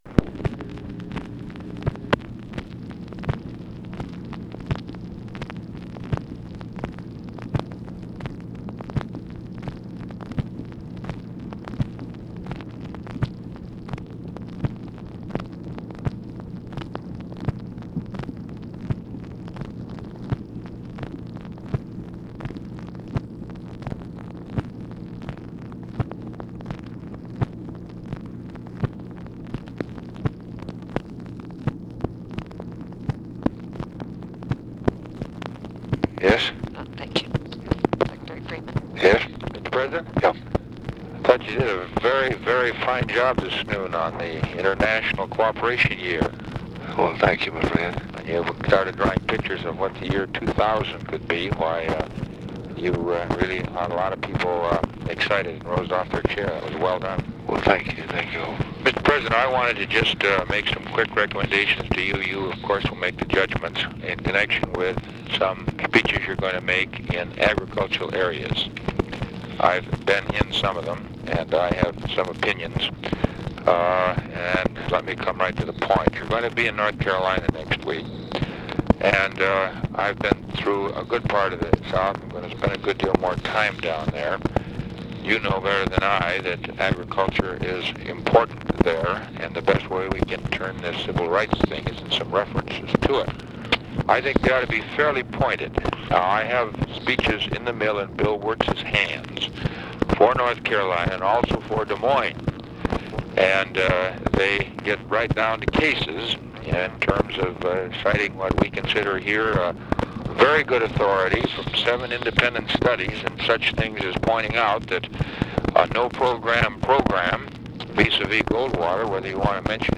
Conversation with ORVILLE FREEMAN, October 2, 1964
Secret White House Tapes